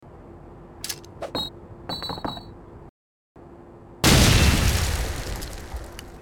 Звуки гранаты
звук метания гранаты